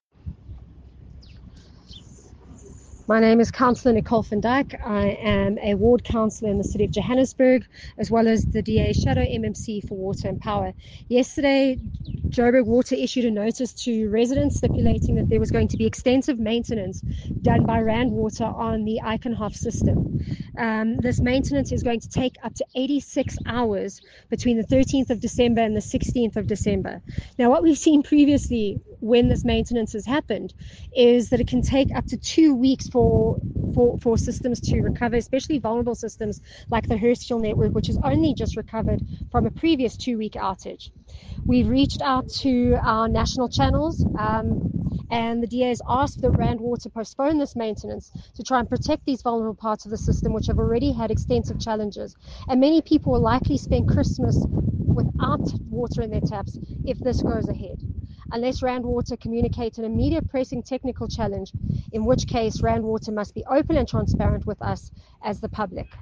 Note to Editors: Please find an English soundbite by Cllr Nicole van Dyk